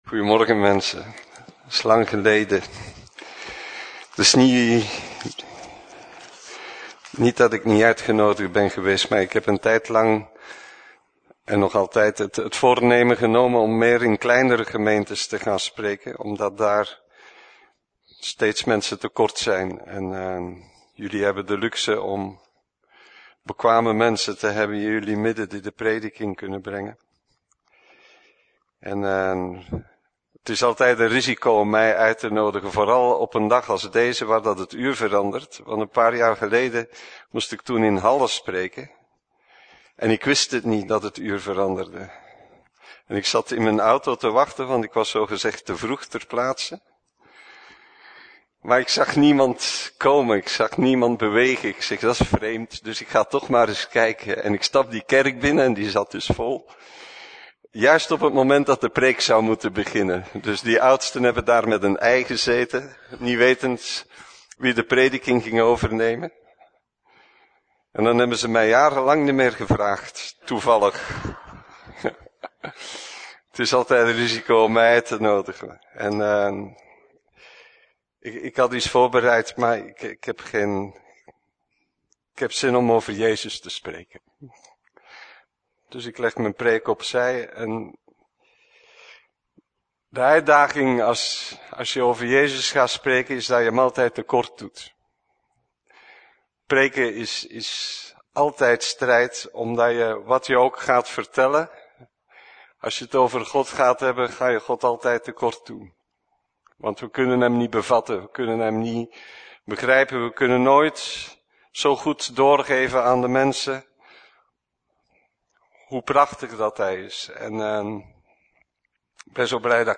Preek: Jezus als persoon - Levende Hoop